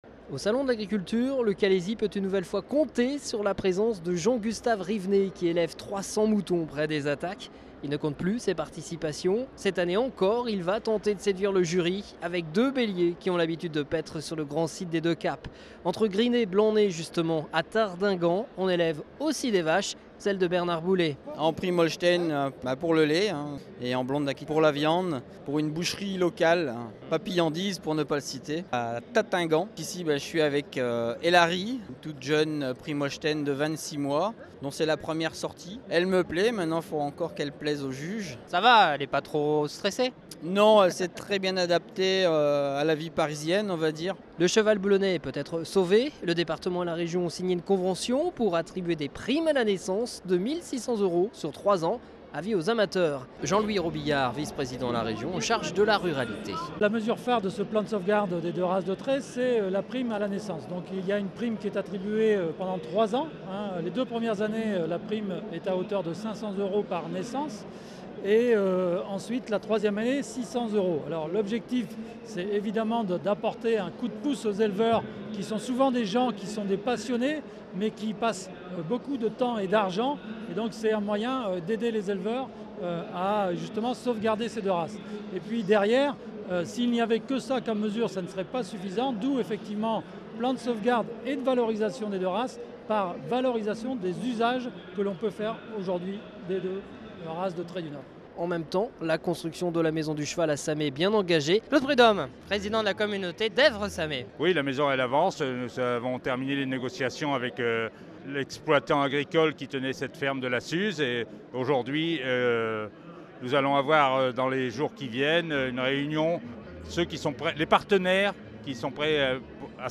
au salon de l'agriculture à Paris